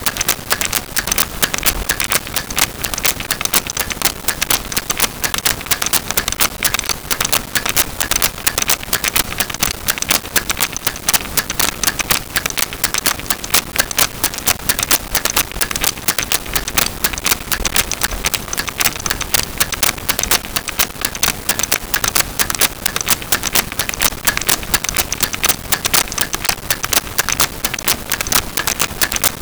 Clock Ticking 4
clock-ticking-4.wav